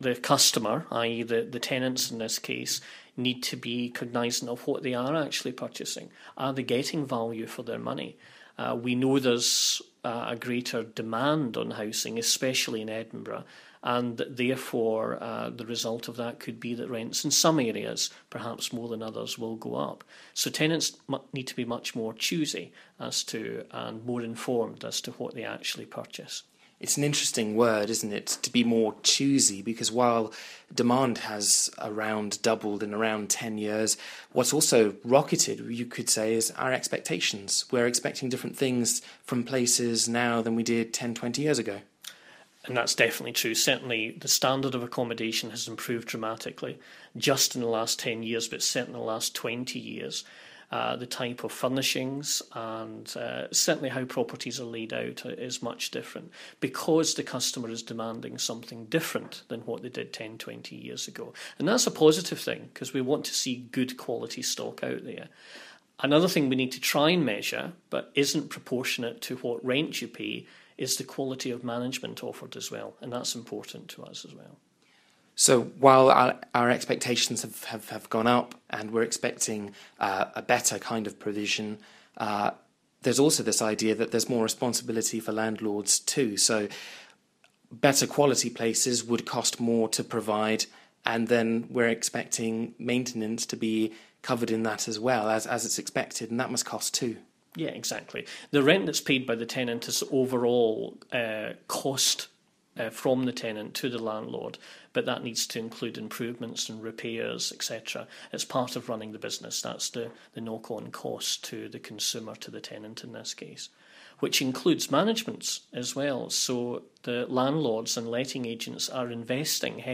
Forth News quizzes rental expert